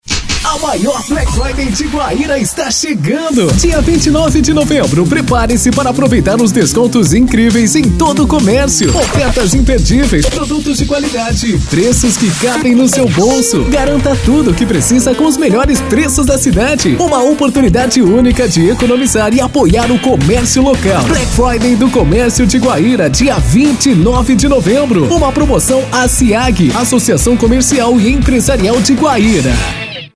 Spot Rádio Campanha
spot_black_friday.mp3